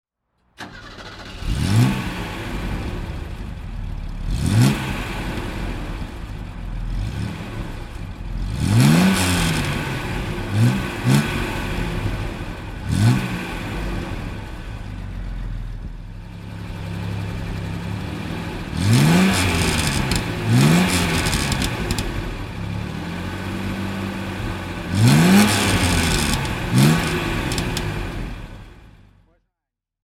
Mercedes-Benz 250 T (1979) - Starten und Leerlauf
Mercedes-Benz_250_T.mp3